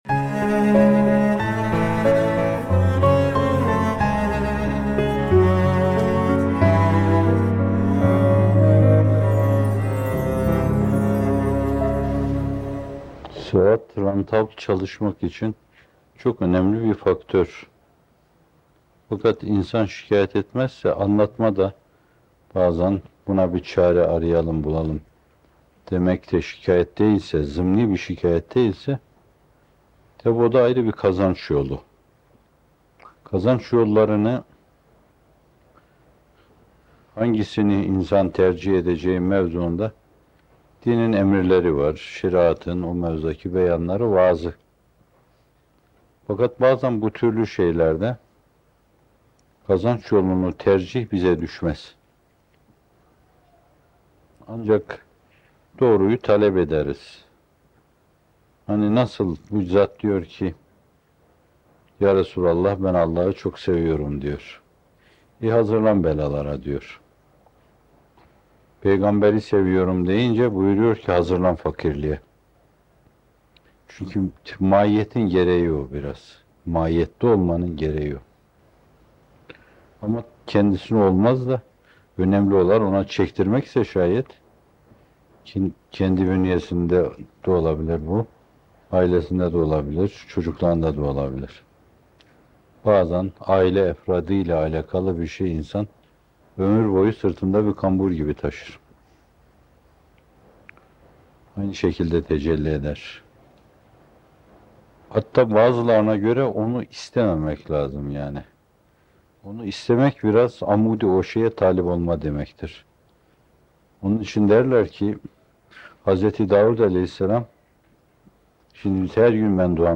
Musibetleri Manevi Kazanca Dönüştürmenin Yolları - Fethullah Gülen Hocaefendi'nin Sohbetleri